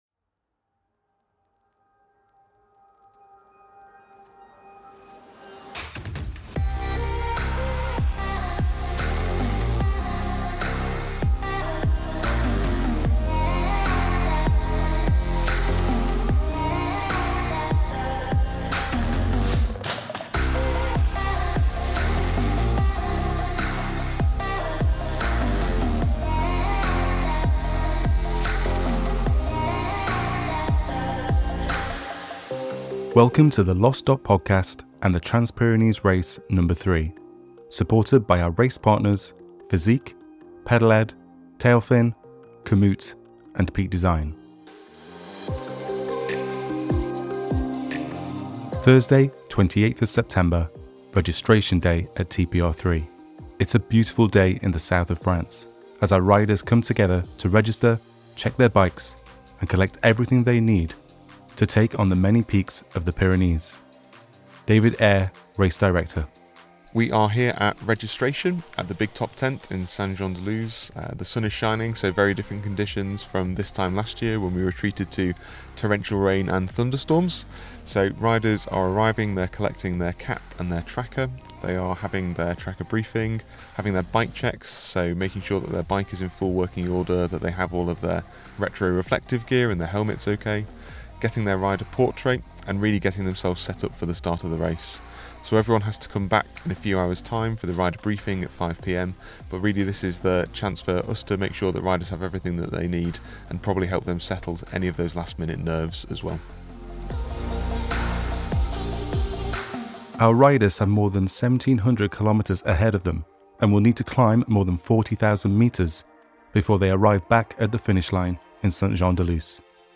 Race Reporters catch up with organisers and riders at registration yesterday as the anticipation mounts and everyone prepares for the Race to begin.